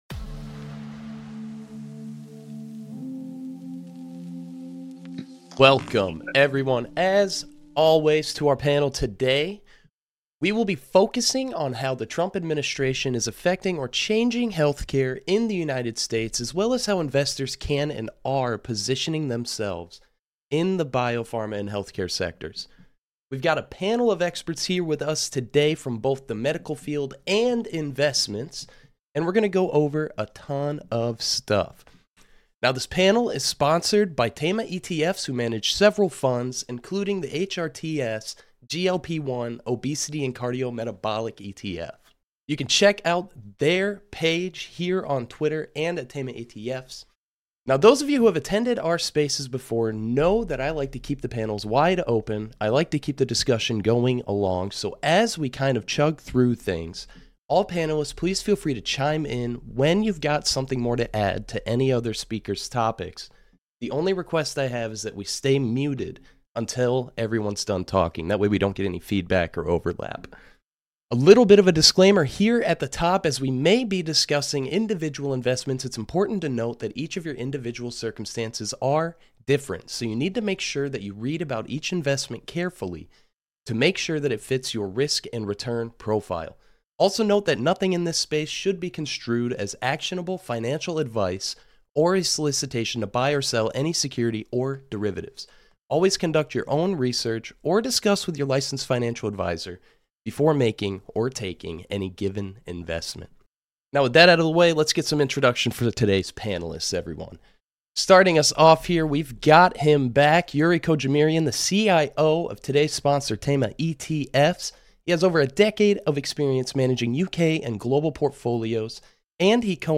This episode of the Unusual Whales Pod was recorded Live on January 14, 2025.